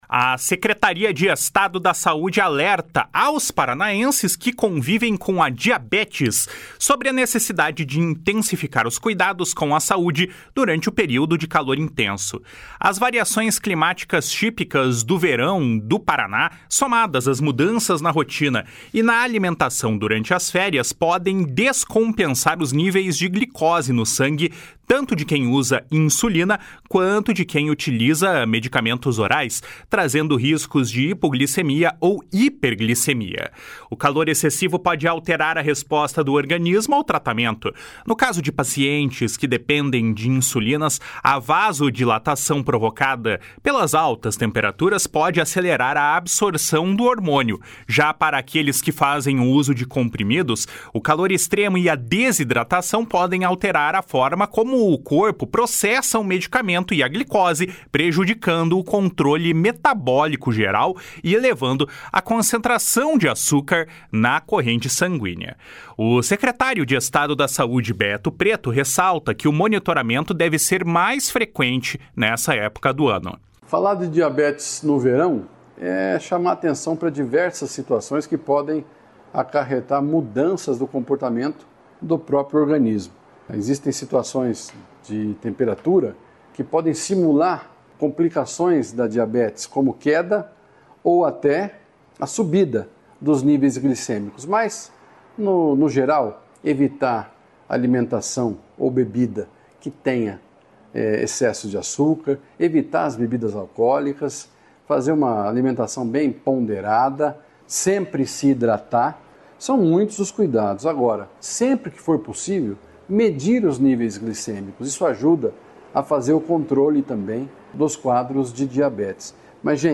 O secretário de Estado da Saúde, Beto Preto, ressalta que o monitoramento deve ser mais frequente nesta época do ano. // SONORA BETO PRETO //